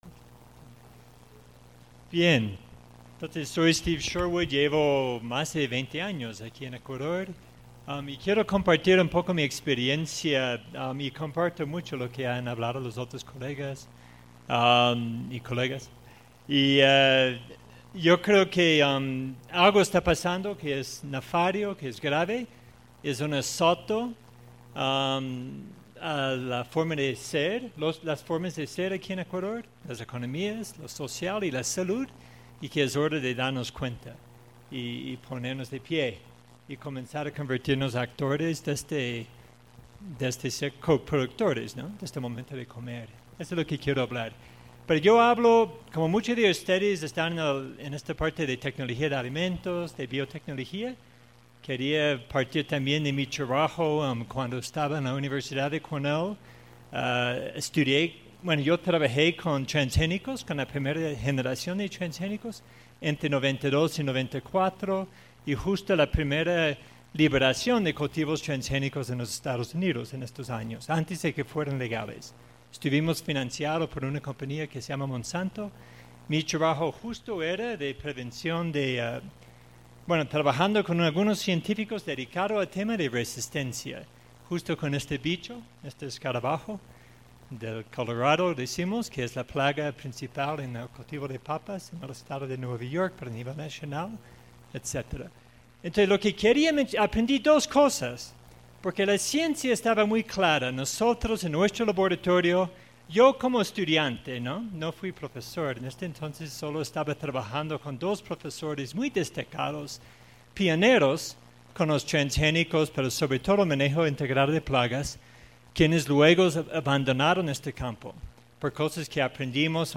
El Departamento de Ciencias Sociales de la Escuela Politécnica Nacional (EPN) y la campaña ¡Qué rico es! convocaron al foro Ciudadanía y Alimentos realizado el 10 de agosto en el Hemiciclo politécnico.